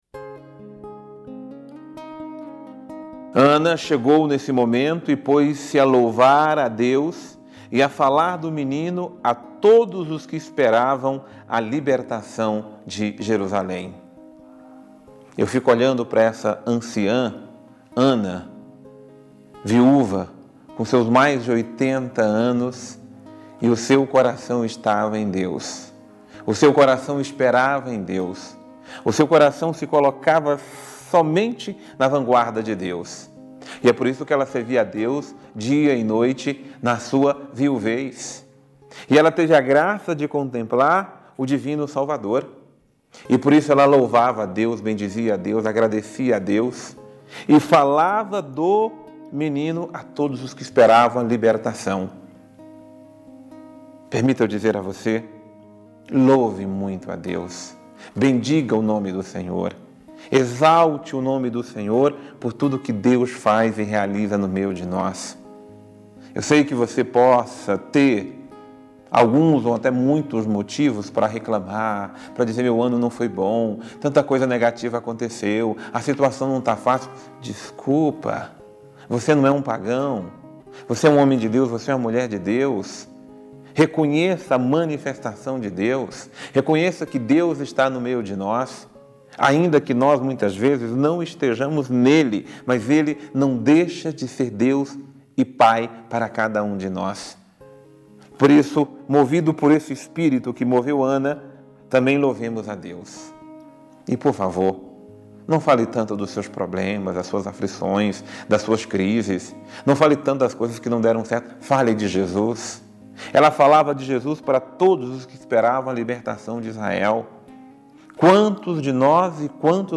Homilia diária - Apresentemos Jesus ao mundo